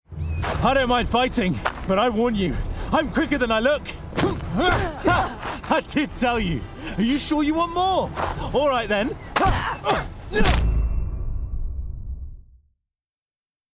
Voice Artist
Computer Games